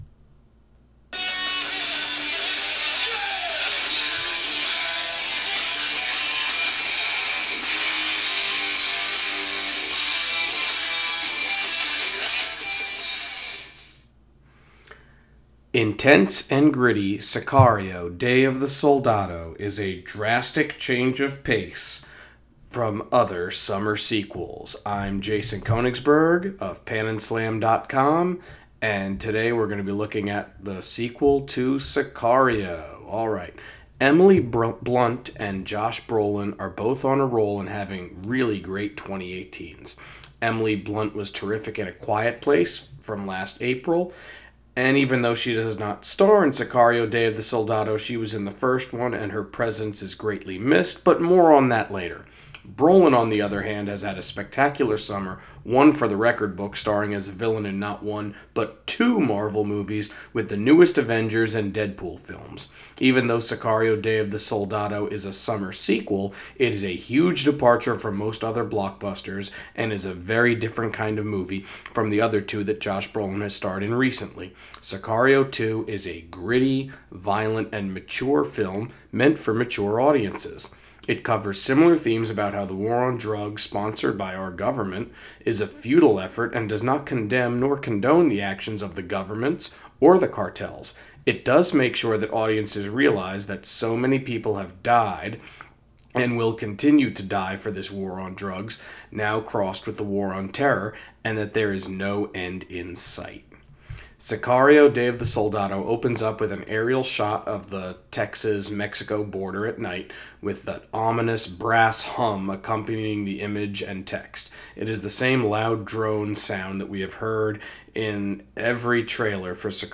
Movie Review: Sicario: Day of the Soldado